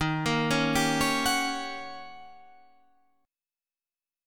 Eb7#9 Chord